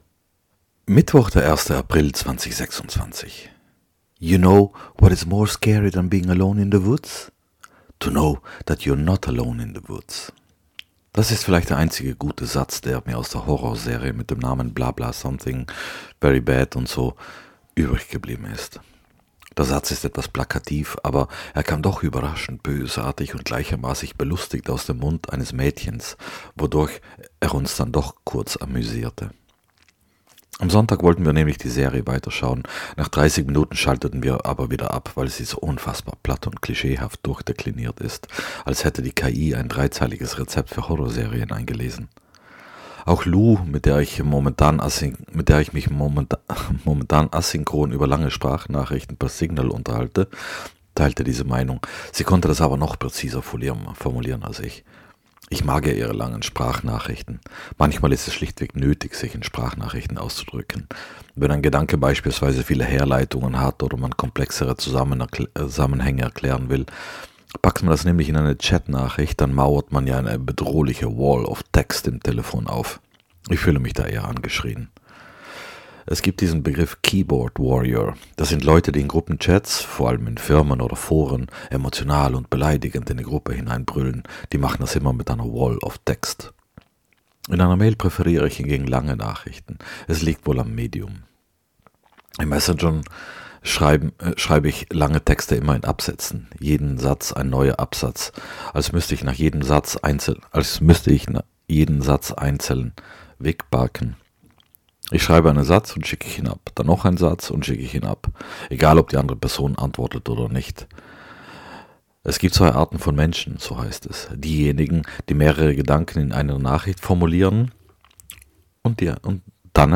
[Mi, 1.4.2026 - Duett aus Opernsängerinnen] - es regnet